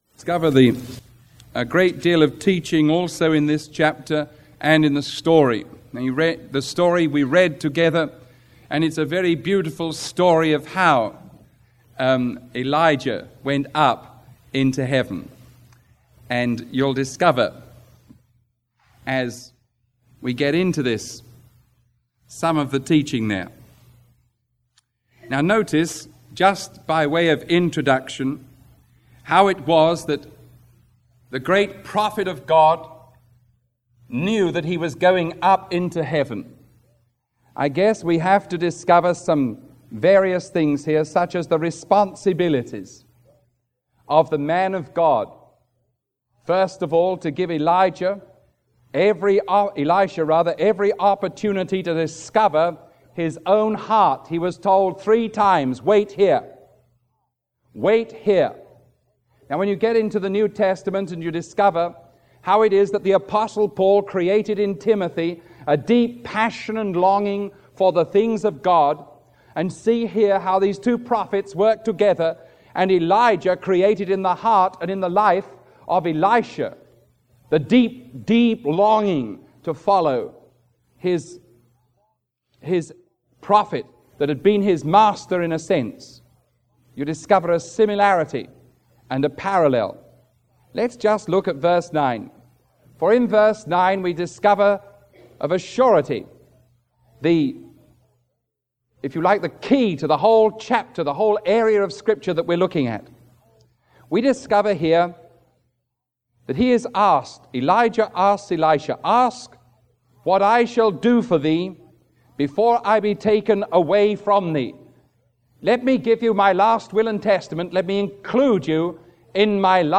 Sermon 0391A recorded on July 26